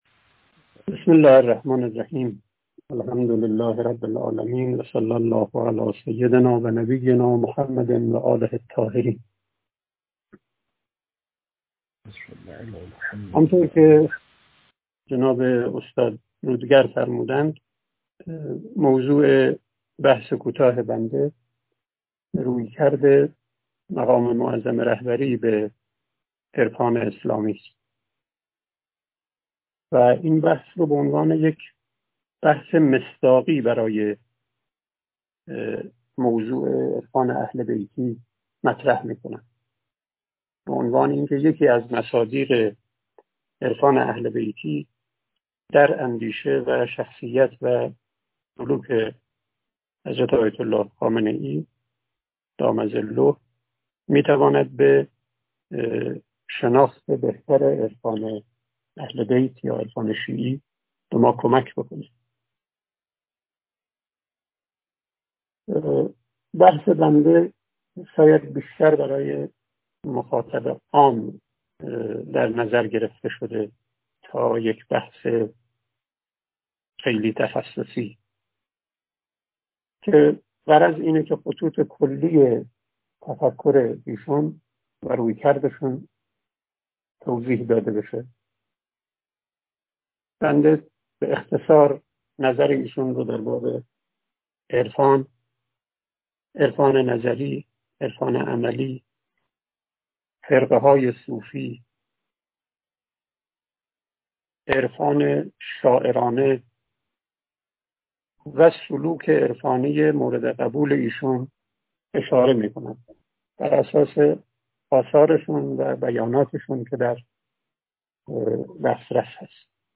گفتاری
ارائه شده در پیش همایش عرفان اهل بیتی